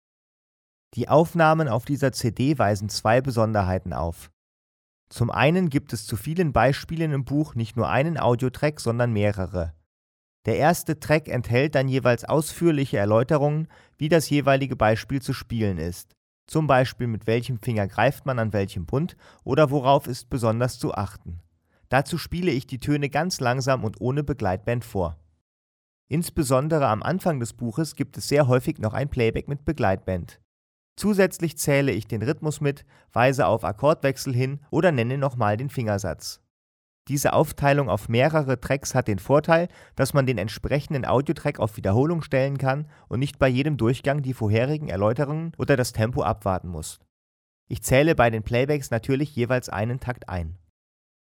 Als MODERIERTE MP3-CD mit mehr als vier Stunden Spieldauer bietet sie präzise Anleitungen zu Fingersätzen, rhythmischen Besonderheiten und viele Playbacks zum Mitspielen.
004_Moderierte_CD_mit_ausfuehrlichen_Anleitungen_1.mp3